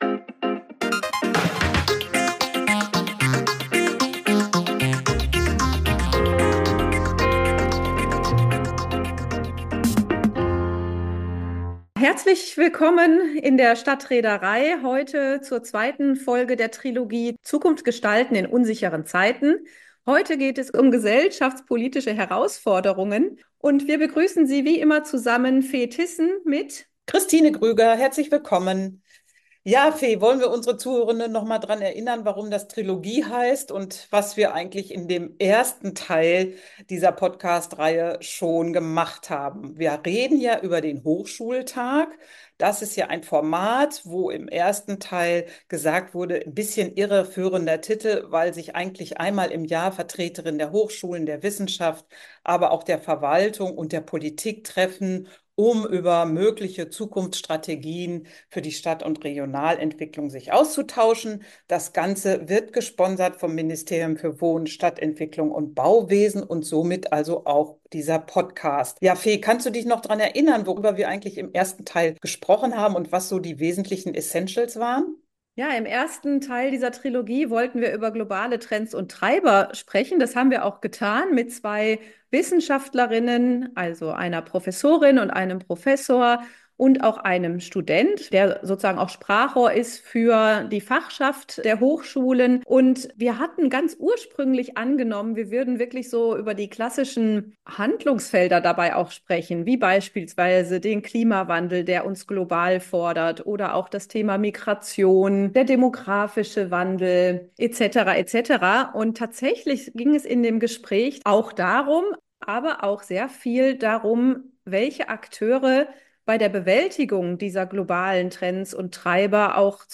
Die Gesprächspartnerinnen stellen im menschlichen Verhalten fest, dass Dünnhäutigkeit, Wut, Rückzug, Resignation und Nachrichtenvermeidung zu „defekten Debatten“ im Miteinander führen. Sie zeigen Wege auf, wie es wieder zum Miteinander und zum Zusammenhalt in gesellschaftlichen Gruppen und in Nachbarschaften kommen kann.